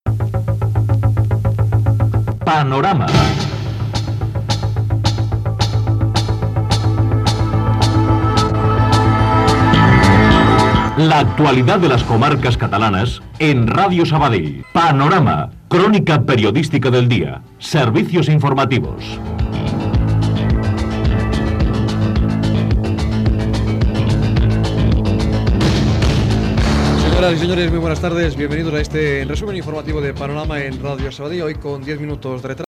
Careta del programa i salutació
Informatiu